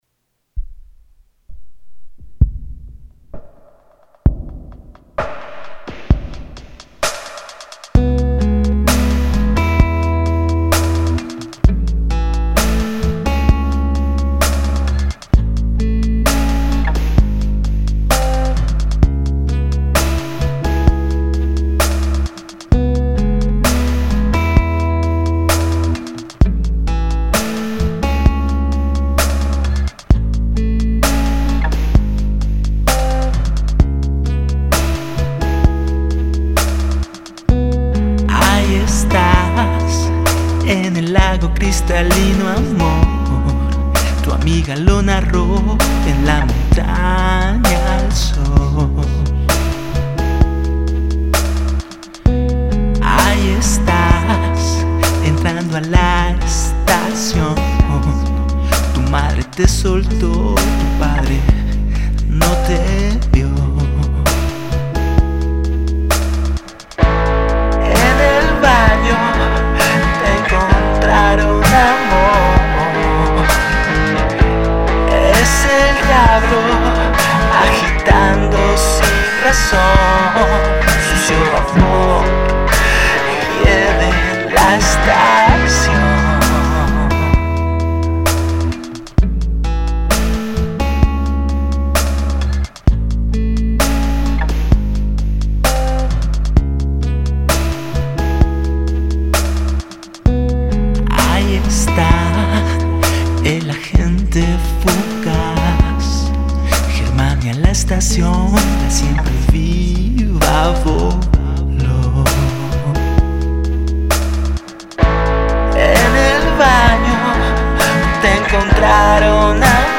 Canción
voz y guitarra.
Guitarra eléctrica.